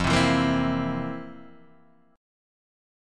F Major 7th Suspended 4th